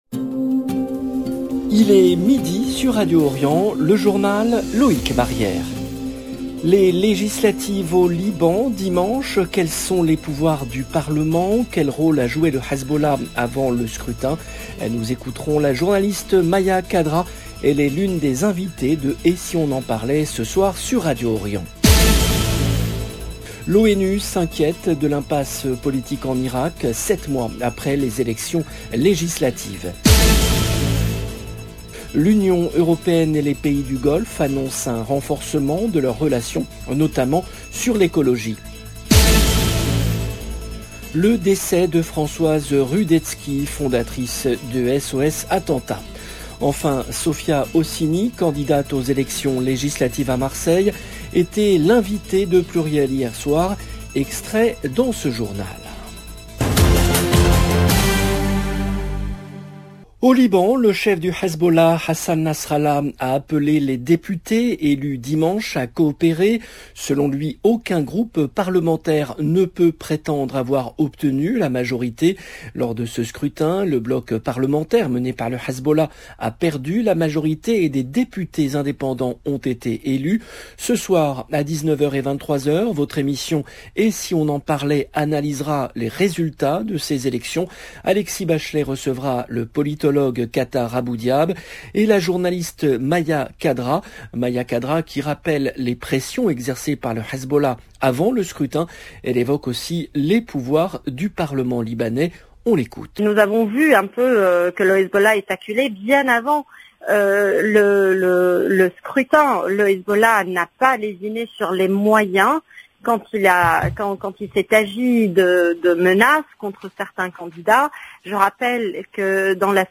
Liban LB JOURNAL EN LANGUE FRANÇAISE 0:00 16 min 6 sec 19 mai 2022